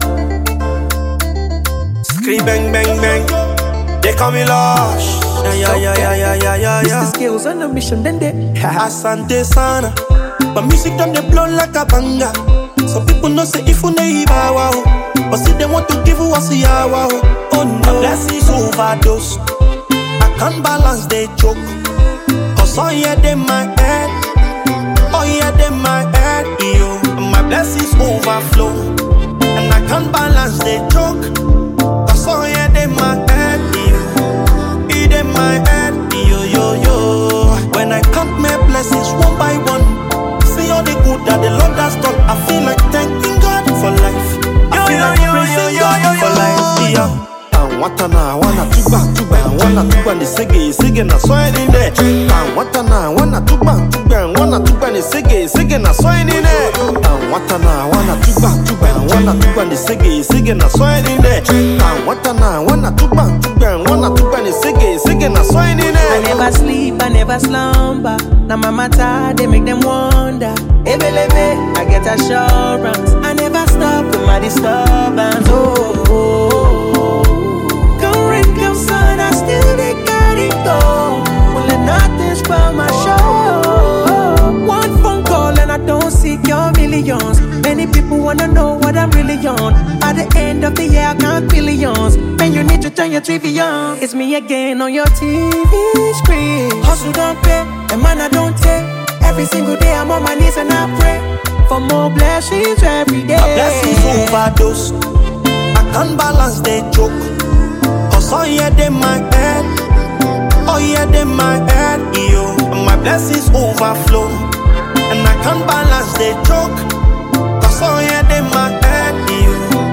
a Nigerian rapper, singer, and songwriter